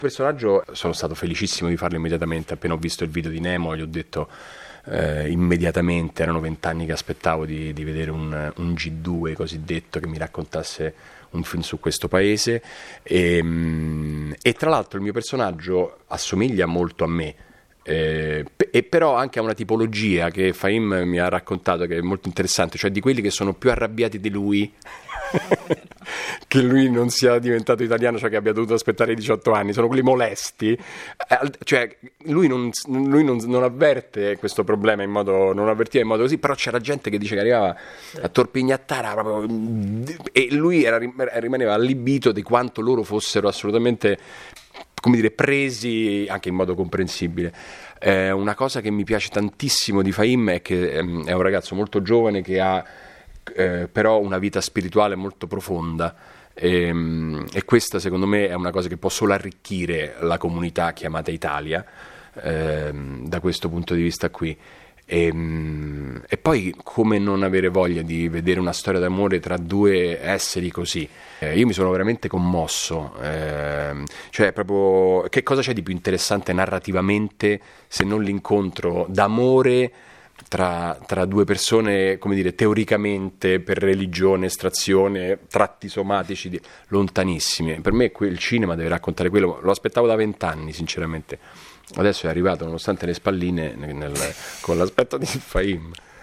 bangla-pietro-sermonti-parla-del-film.mp3